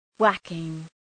{‘wækıŋ}